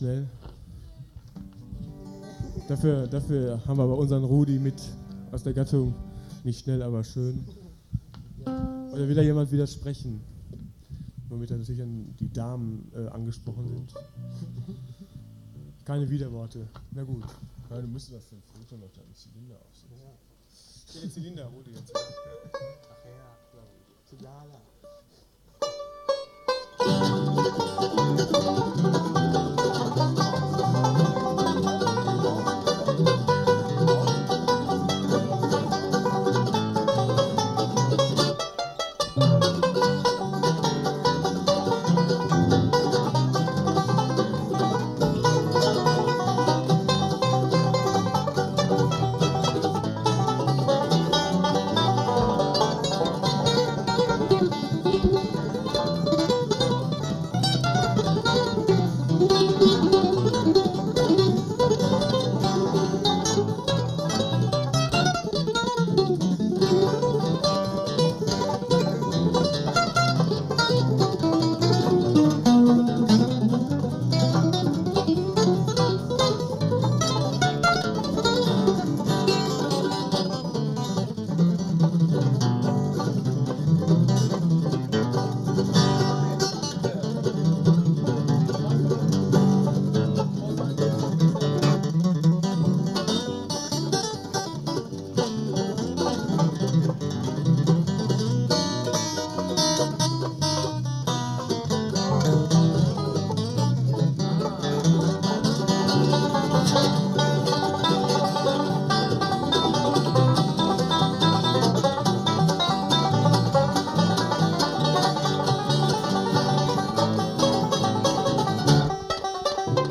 Fivestring Banjo
Höllentempo, aber es klappte.